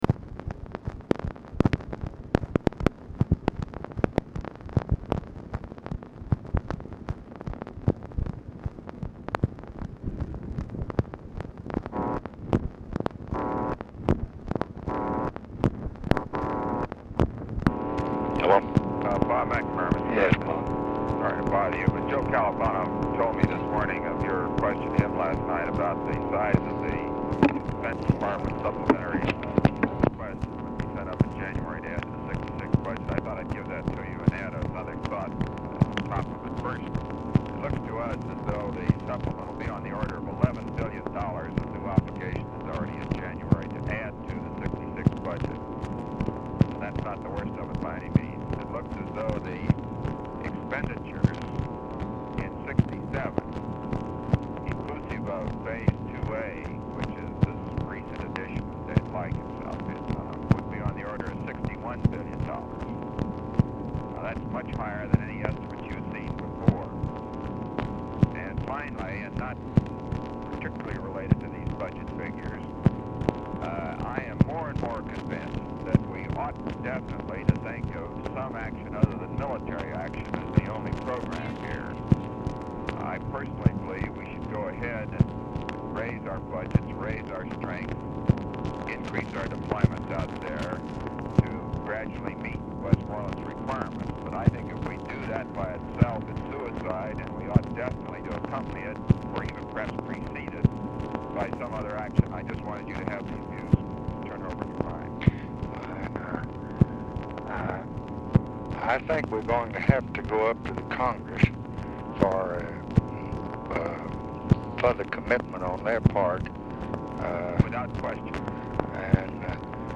POOR SOUND QUALITY; MCNAMARA ON HOLD 0:20
Format Dictation belt
Location Of Speaker 1 LBJ Ranch, near Stonewall, Texas
Specific Item Type Telephone conversation Subject Congressional Relations Defense Diplomacy Economics Federal Budget Legislation Vietnam